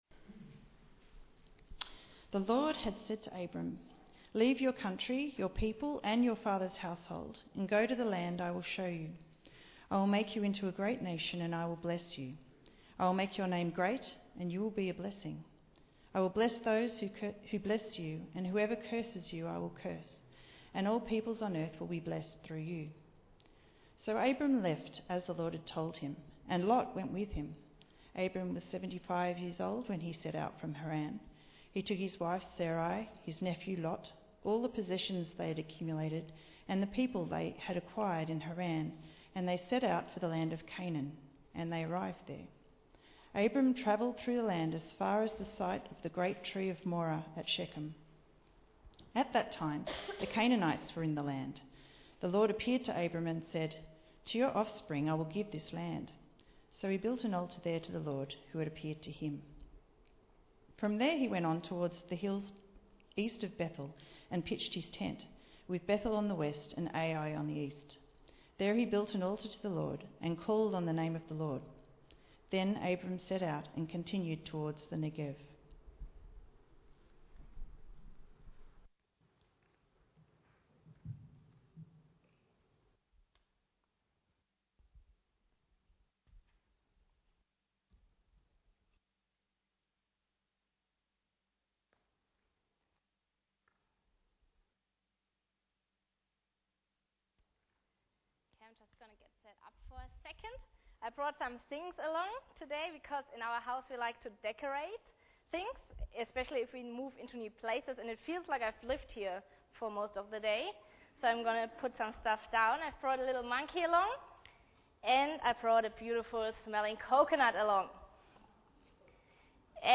Bible Text: Genesis 12:1-9 | Preacher: